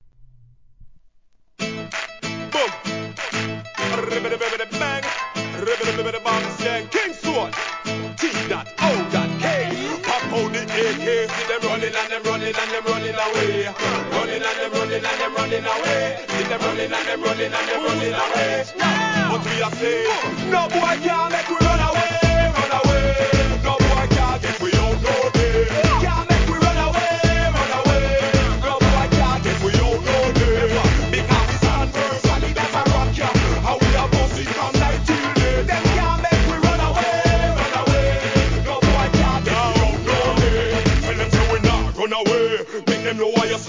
REGGAE
情熱ギターの好JUGGLIN!!